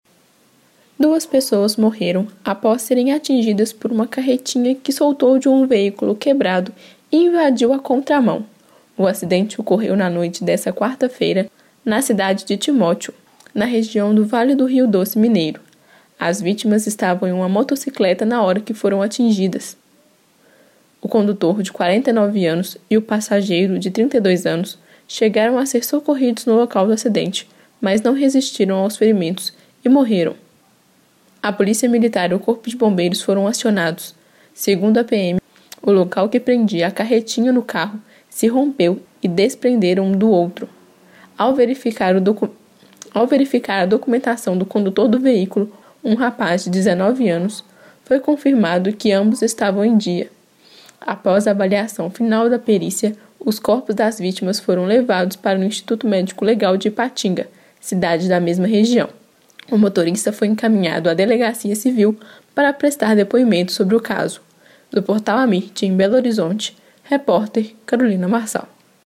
AMIRT Notícias em áudio Policial Vale do Rio DoceThe estimated reading time is 1 minute